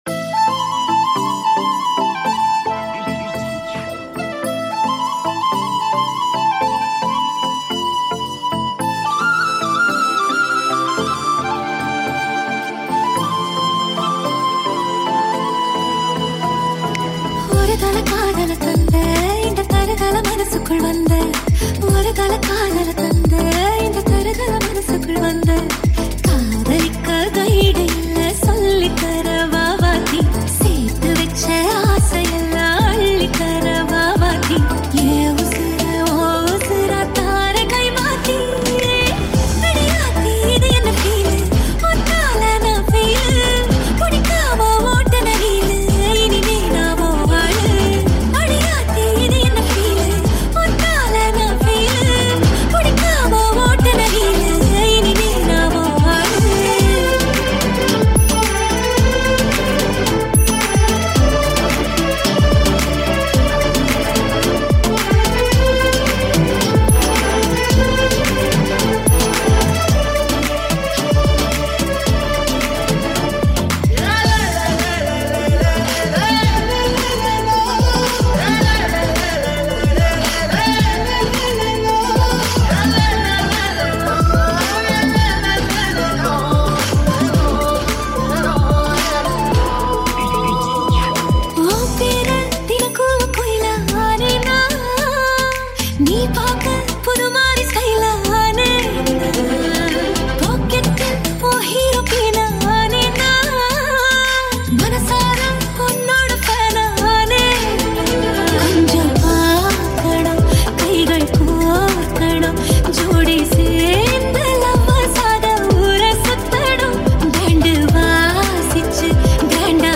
High quality Sri Lankan remix MP3 (2.9).